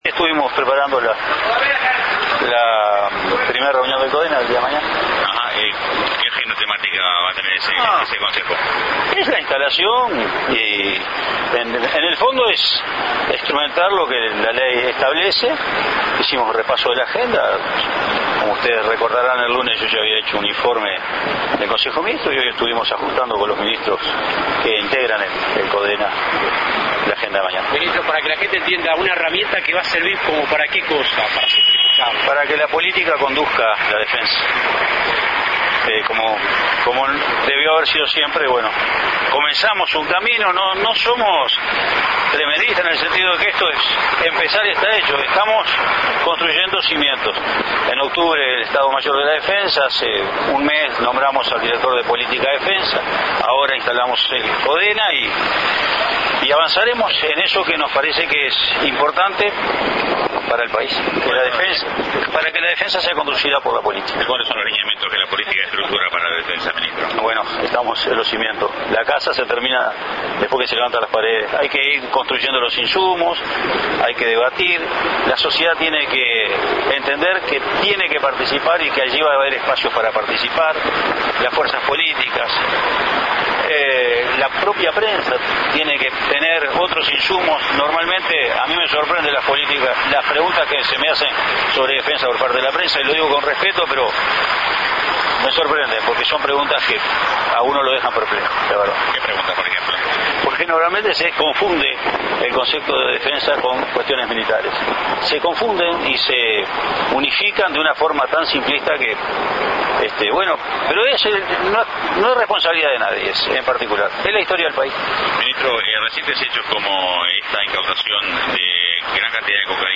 Declaraciones a la prensa del Ministro de Defensa Nacional, Luis Rosadilla, luego de la reunión de integrantes del CODENA mantenida con el Presidente José Mujica.